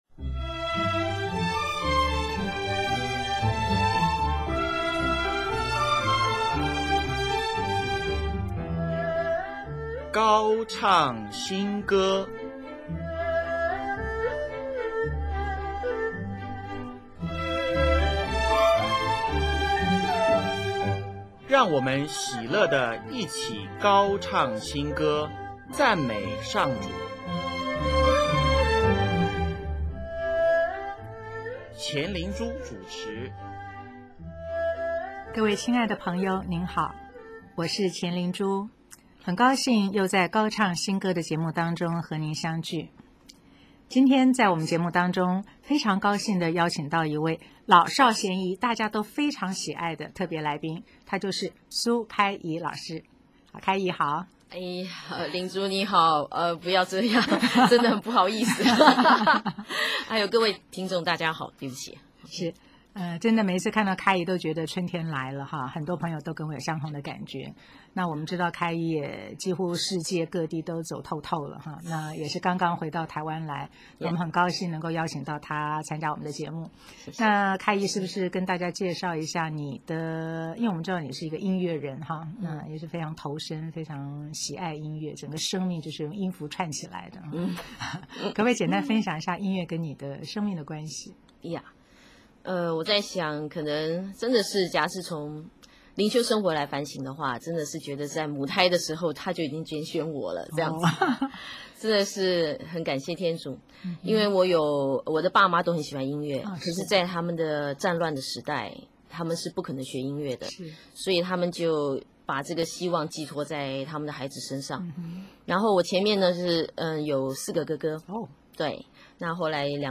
本集播放国际家庭年弥撒曲选曲。